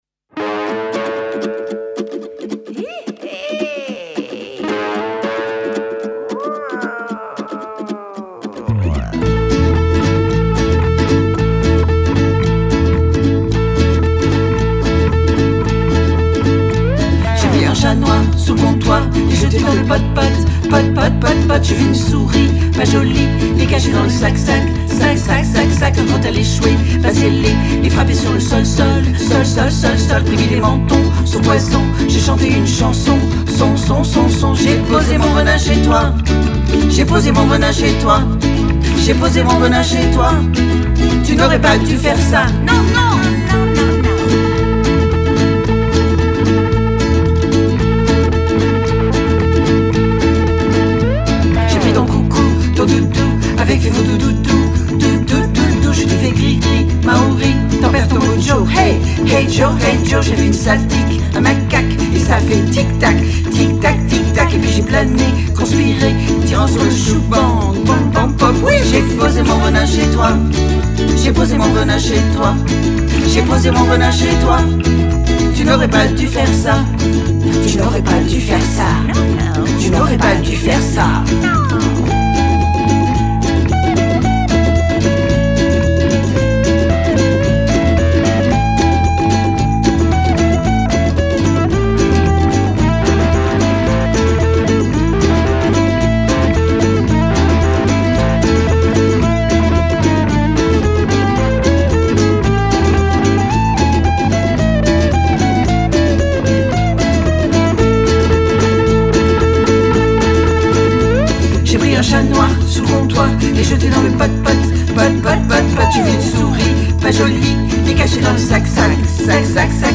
basse électrique, ukulélé
lap steel guitar, ukulélé
batterie, percussions